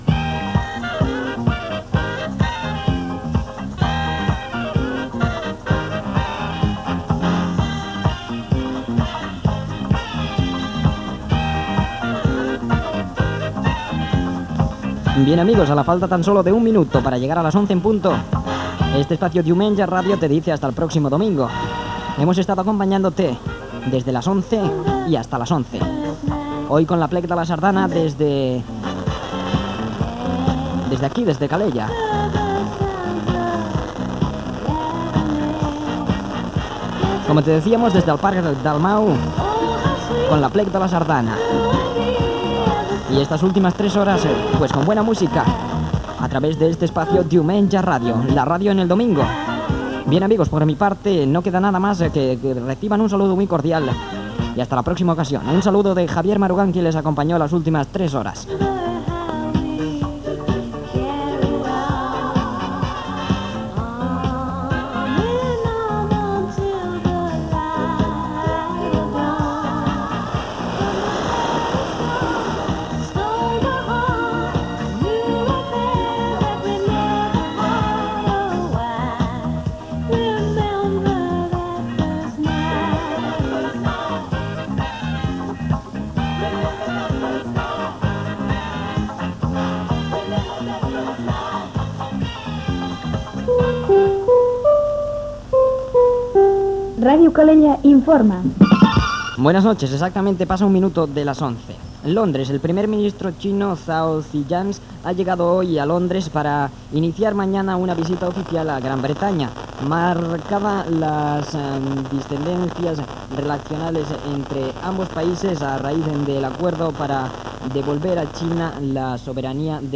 Comiat del programa, "Ràdio Calella Informa" i indicatiu.
FM